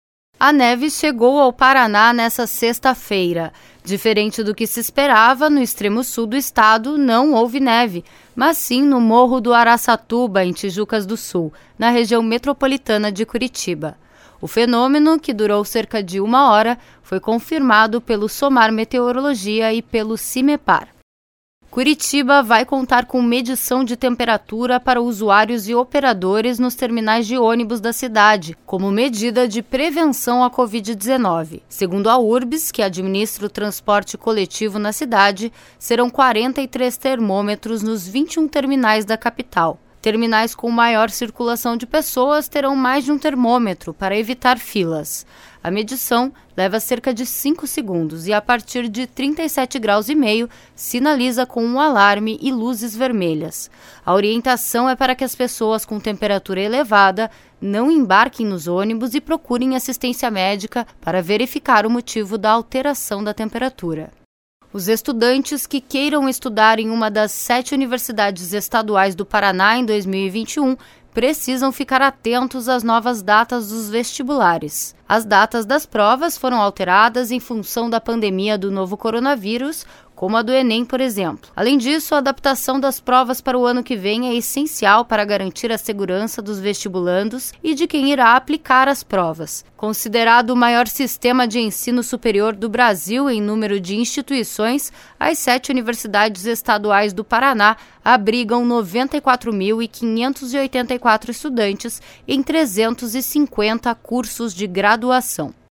Giro de Notícia SEM TRILHA